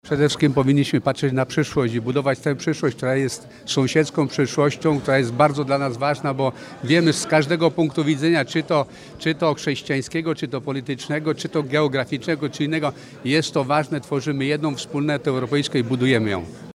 Z okazji 60. rocznicy orędzia biskupów polskich do niemieckich w gmachu Muzeum Archidiecezjalnego we Wrocławiu odbyła się konferencja „Odwaga wyciągniętej ręki”.
O znaczeniu Orędzia Pojednania, w 60. rocznicę wydania listu, mówi przewodniczący Konferencji Episkopatu Polski, abp Tadeusz Wojda SAC.